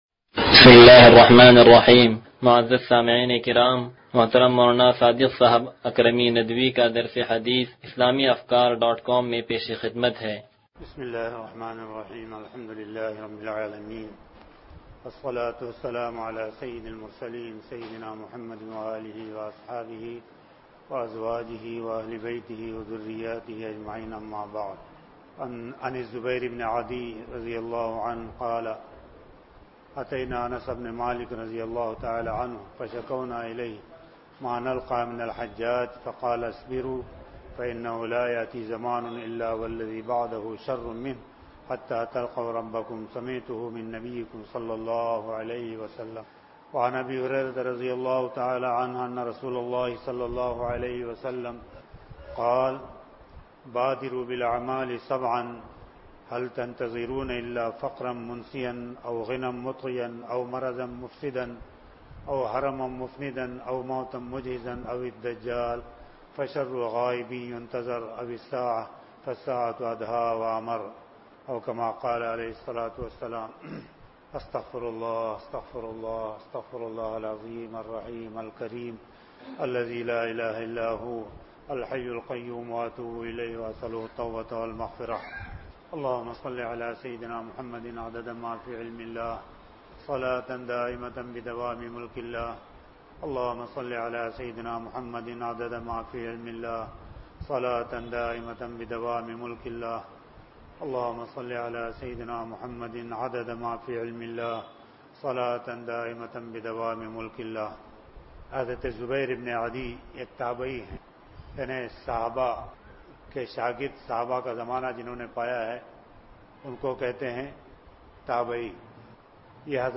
درس حدیث نمبر 0099